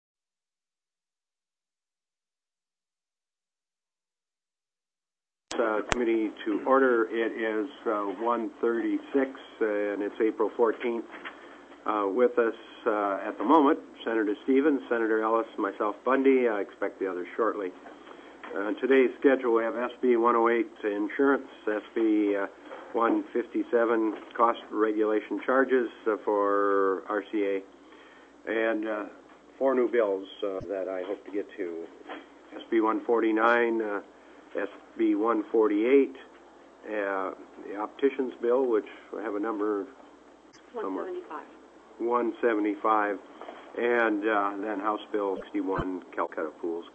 04/14/2005 01:30 PM Senate LABOR & COMMERCE
+ teleconferenced